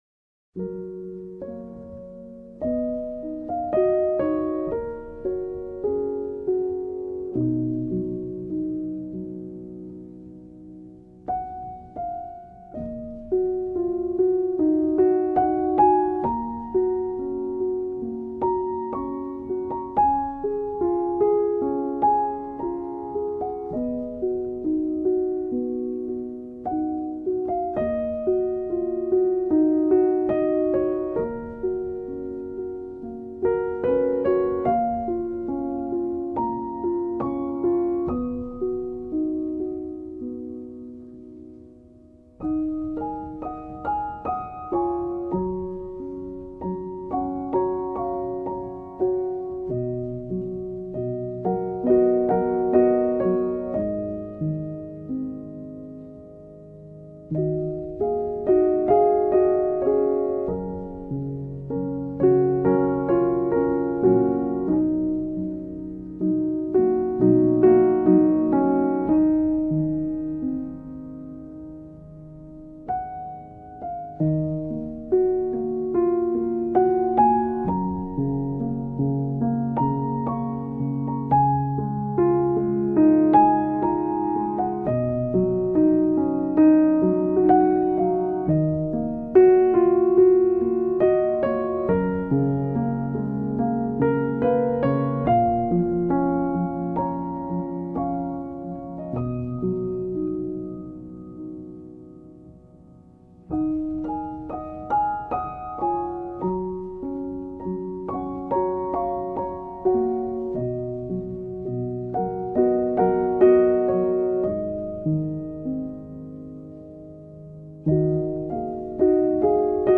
آرامش بخش
Classical Crossover
پیانو